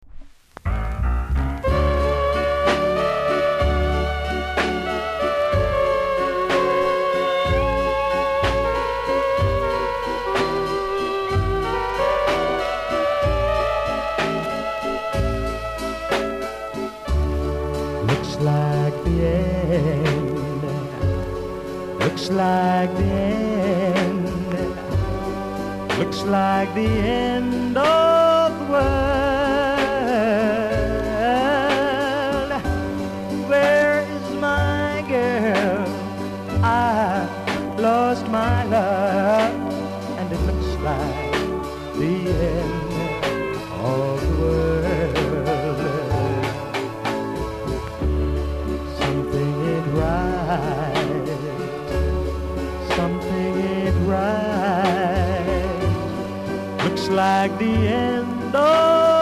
※全体的に薄くノイズあります。小さなチリノイズが少しあります。盤は薄い擦り傷、クモリが少しありますがキレイなほうです。